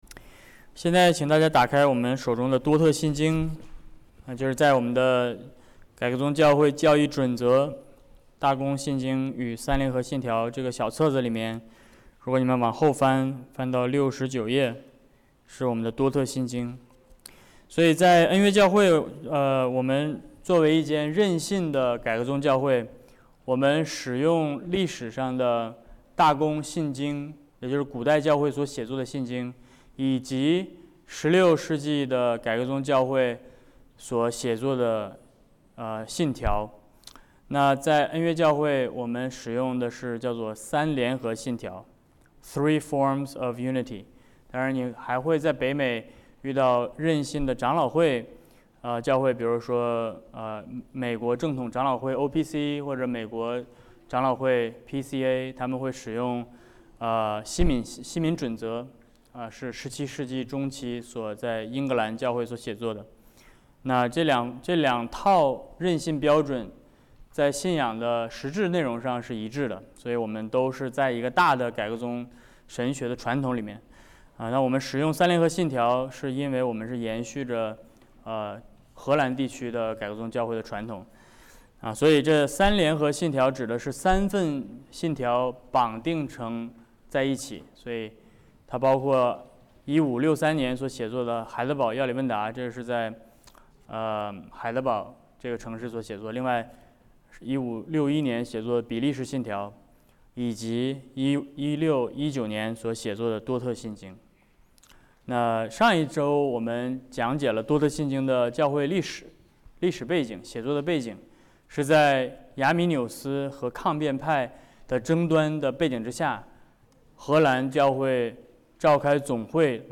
Service Type: 主日学课程